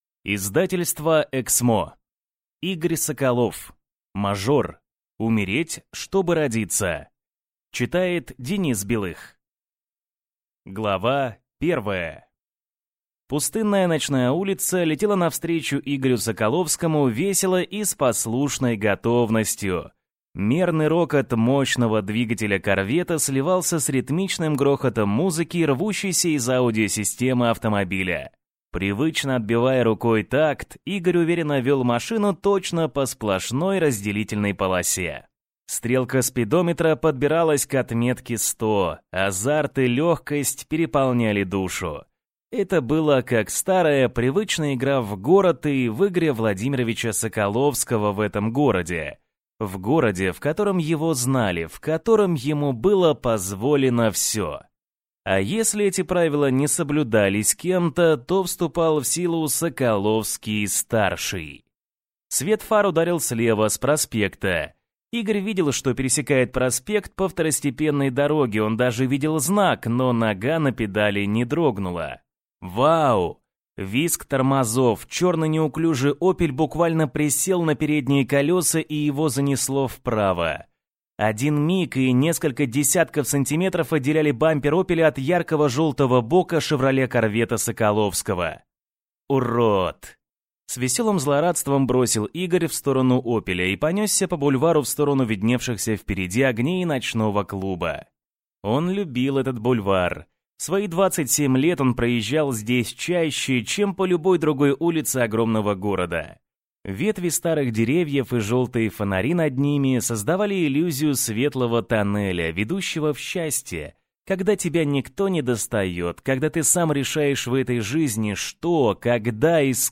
Аудиокнига Мажор. Умереть, чтобы родиться | Библиотека аудиокниг